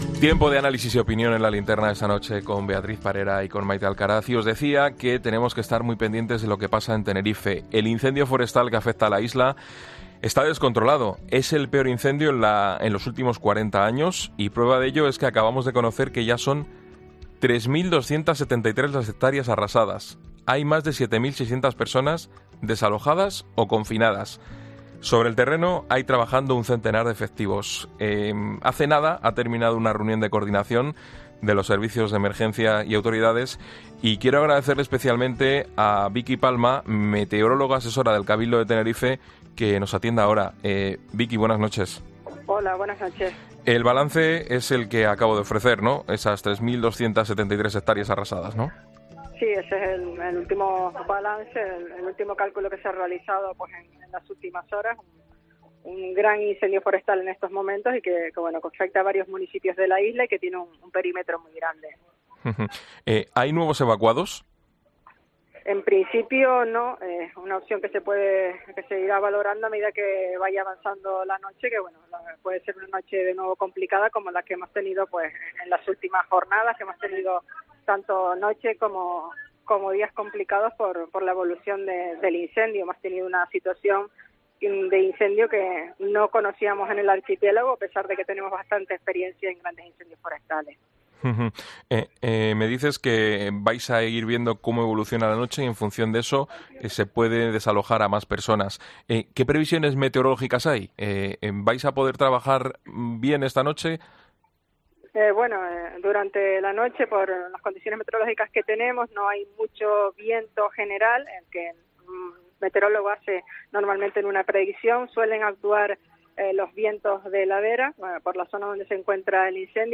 La experta ha detallado en 'La Linterna' de COPE cuál es la situación en la isla de Tenerife debido al peor incendio de los últimos 40 años